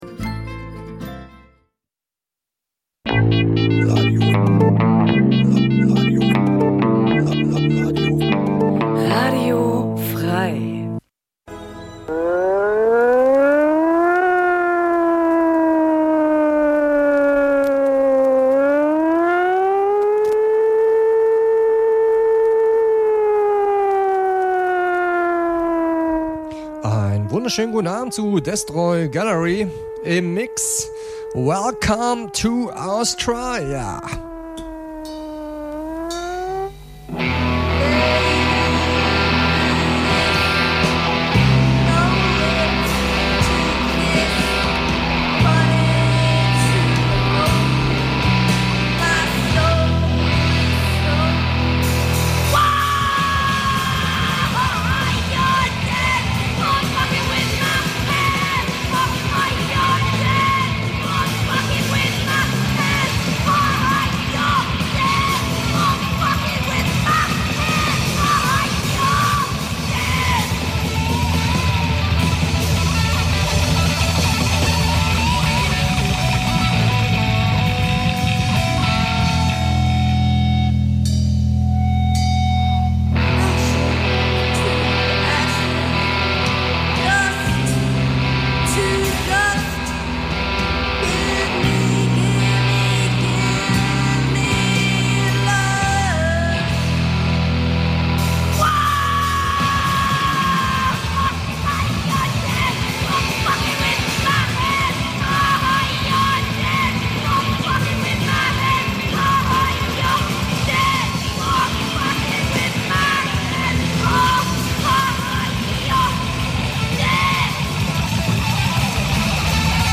Punk '77 - '79 Dein Browser kann kein HTML5-Audio.